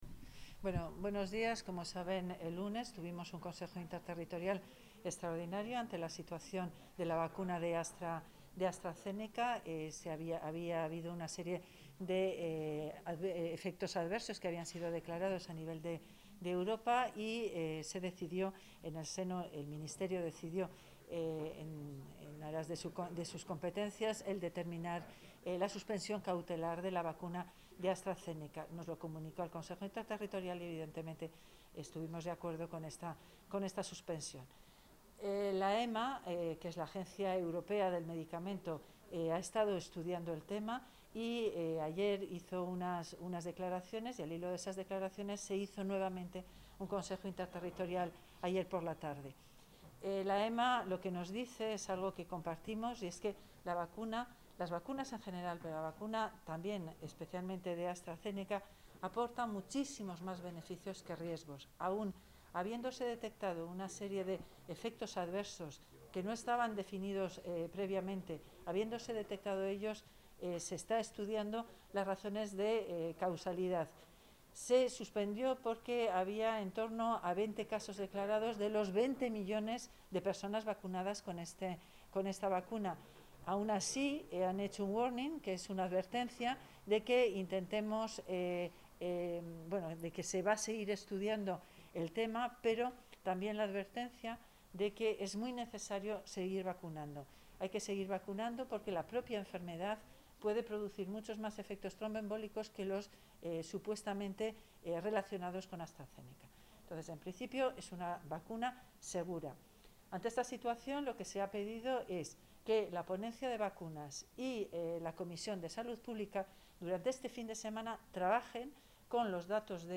Valoración de la consejera de Sanidad sobre la reanudación de la vacunación con AstraZeneca
Valoración de la consejera de Sanidad sobre la reanudación de la vacunación con AstraZeneca Contactar Escuchar 19 de marzo de 2021 Castilla y León | Consejería de Sanidad Se adjunta la valoración de la consejera de Sanidad, Verónica Casado, sobre la reanudación de la vacunación con Astra Zeneca.